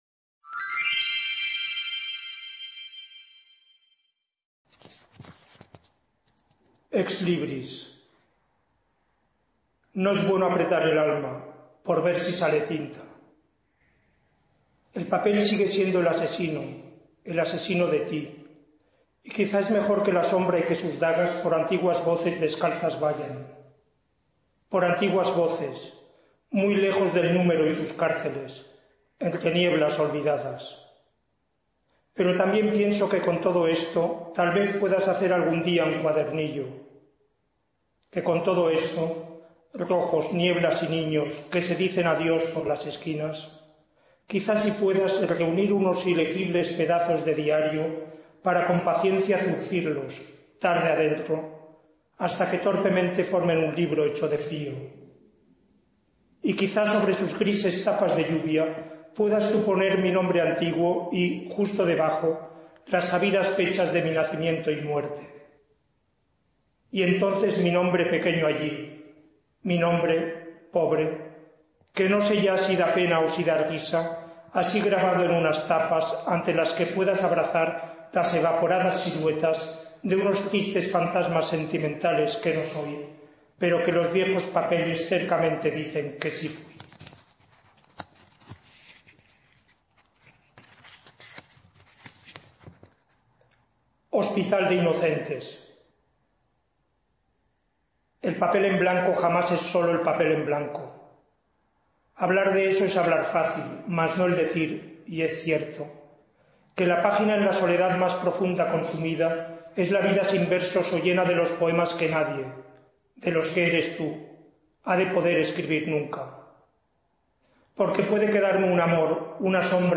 LECTURA DEL LIBRO "DESDE MI VENTANA OSCURA".